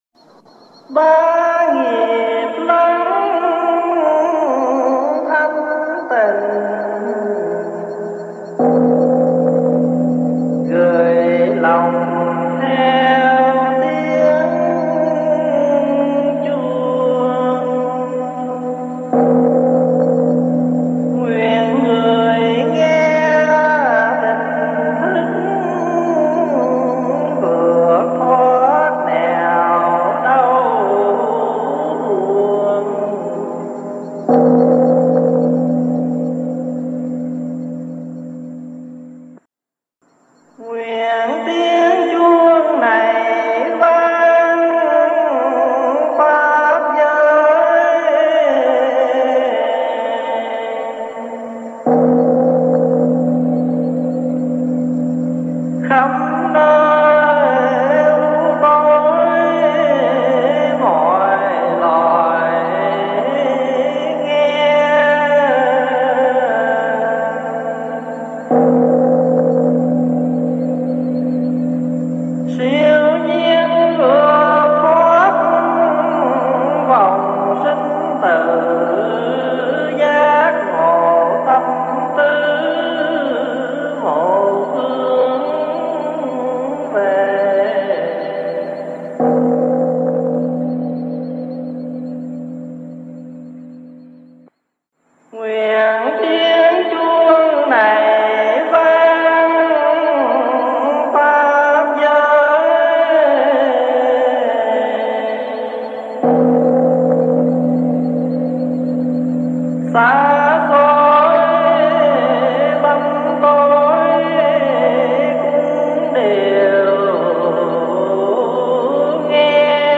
Các Băng Tụng - Trang 1 - Tu Viện Trúc Lâm - Viện Phật Học Edmonton